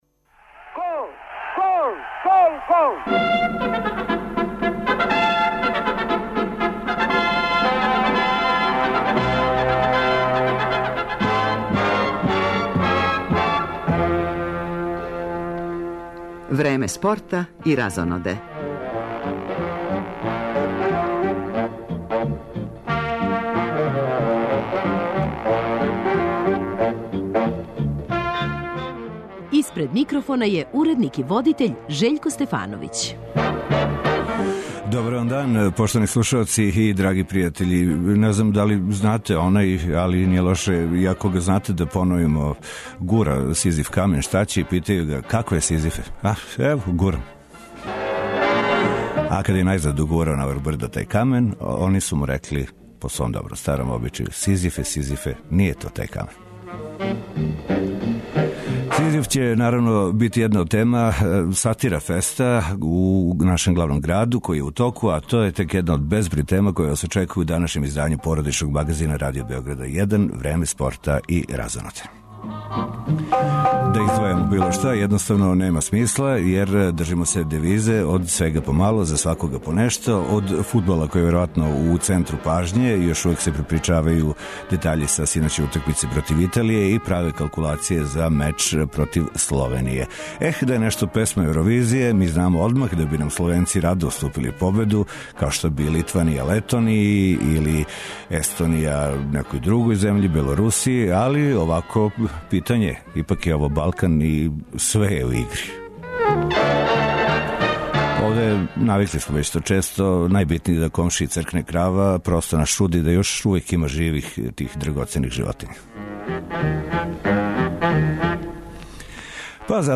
Суботњи породични магазин Радио Београда 1 отварамо утисцима са синоћ одигране фудбалске утакмице између репрезентација Србије и Италије, уз коментаре стручњака и изјаве актера овог важног дуела.